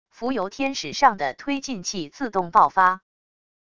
浮游天使上的推进器自动爆发wav音频